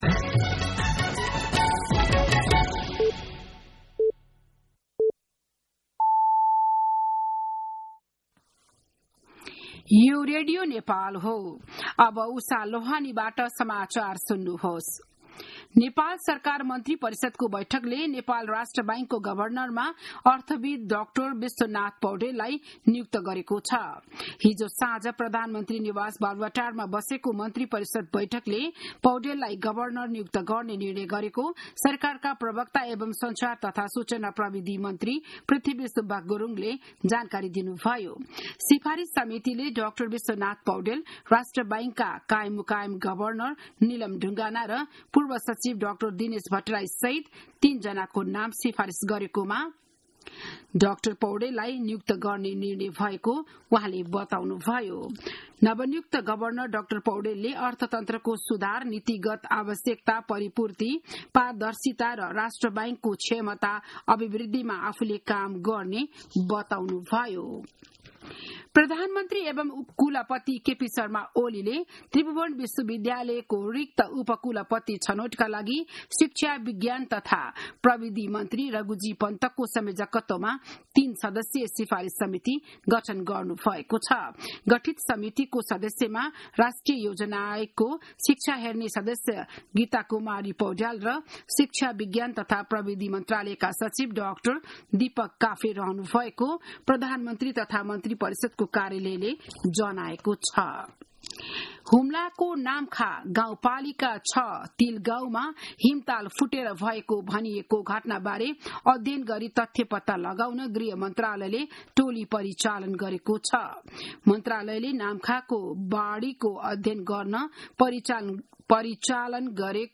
बिहान ११ बजेको नेपाली समाचार : ७ जेठ , २०८२